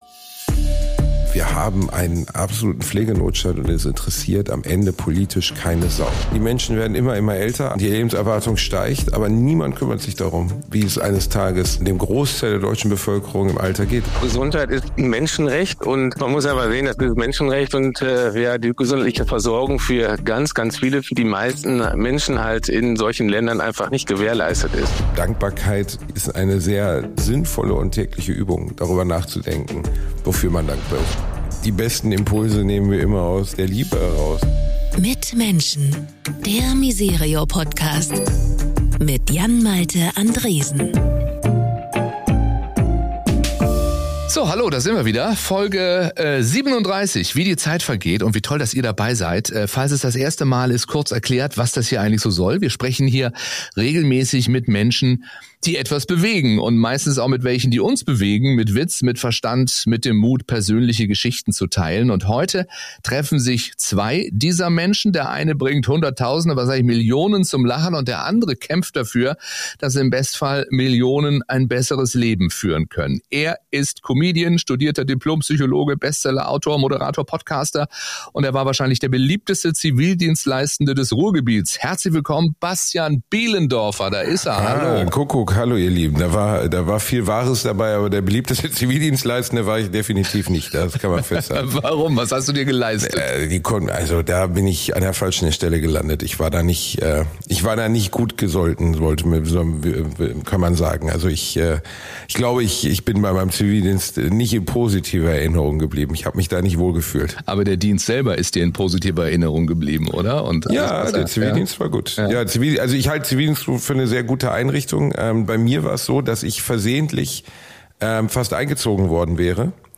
Prominente und ihr Engagement Der Talk-Podcast von misereor Author: misereor Language: de Genres: Government , Society & Culture Contact email: Get it Feed URL: Get it iTunes ID: Get it Get all podcast data Listen Now...
Ein Gespräch über Neugier, echte Teilhabe – und darüber, wie viel Engagement zurückgeben kann.